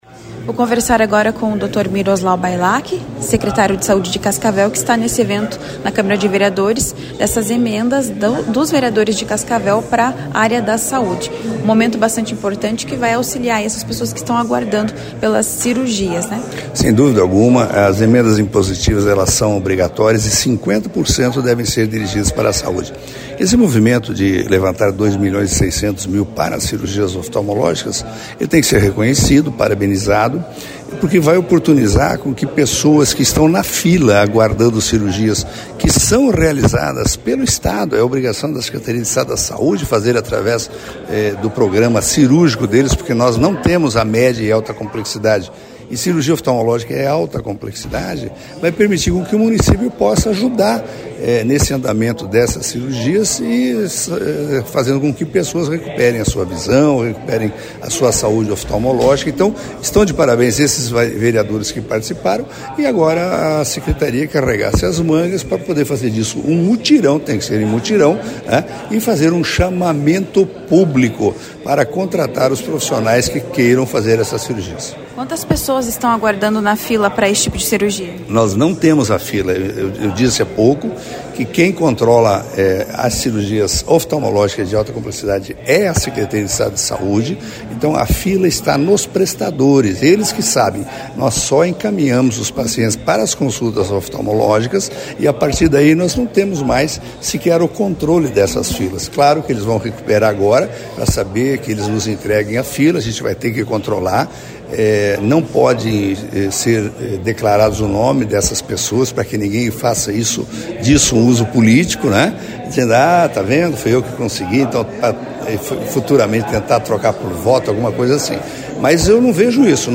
Em evento na Câmara de Vereadores de Cascavel na manhã desta quinta-feira (12), além da destinação de recursos à Saude por parte dos vereadores mediante emendas impositivas, o prefeito eleito Renato Silva anunciu Ali Haidar como secretário de Saúde para a proxima gestão, a partir de janeiro, acompanhe.